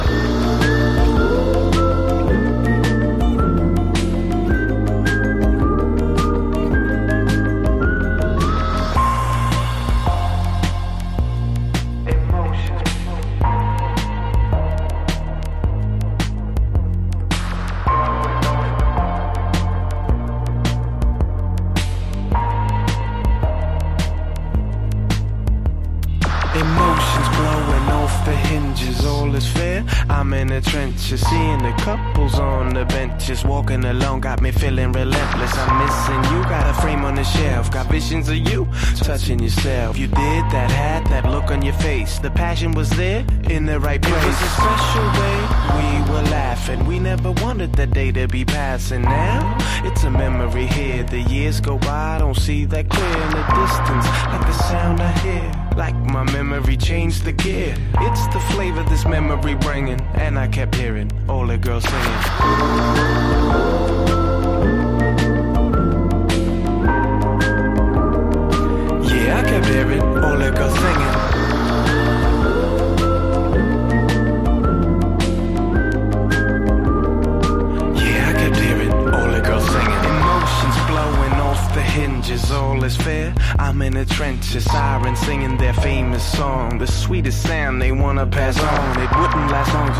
フワフワした浮遊感溢れるチルアウト・ラウンジ・ダウンテンポ!!
CROSSOVER / LOUNGE# HEADZ / ELECTRONICA / CHILOUT